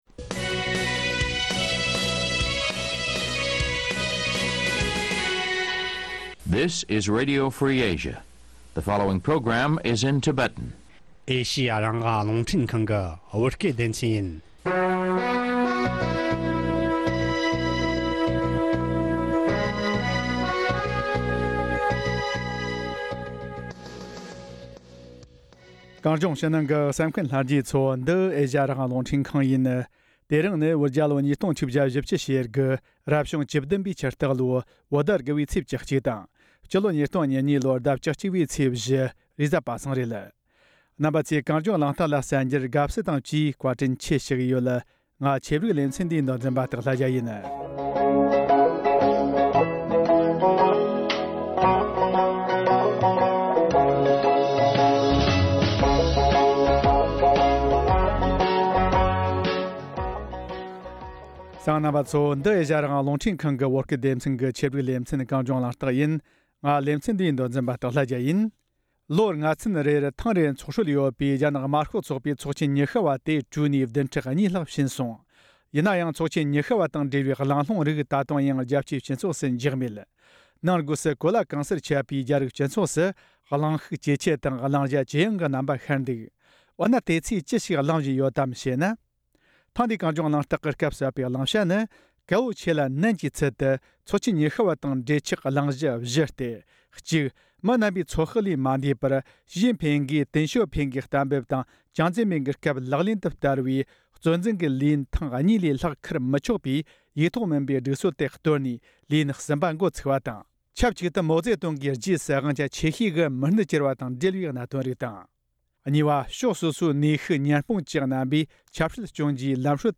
བགྲོ་གླེང་བྱས་པ།